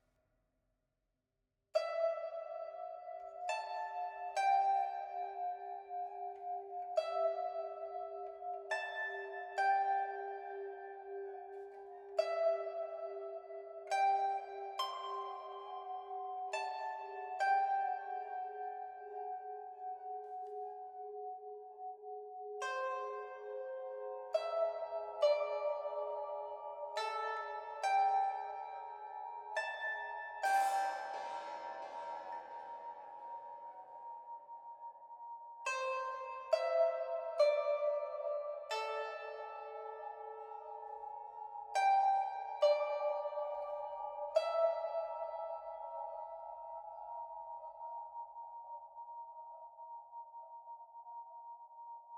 摇啊摇诡异pipa.wav